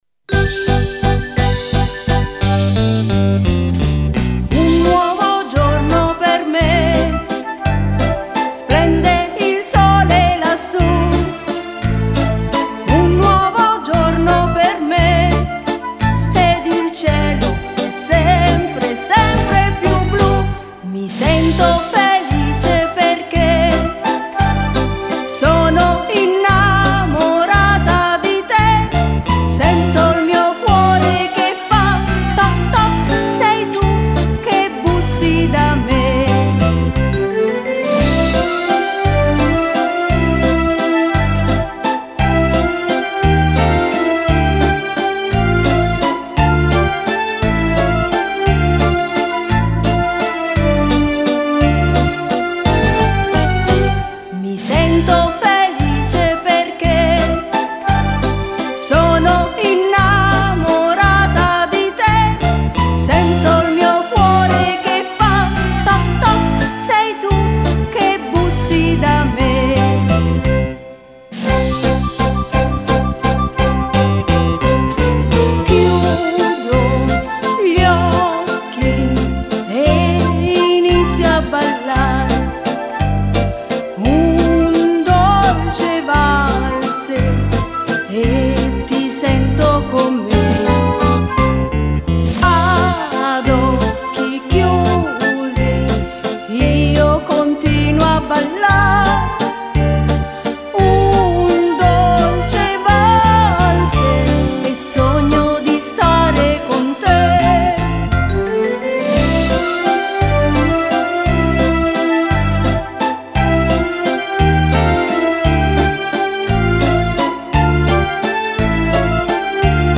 (Canzone)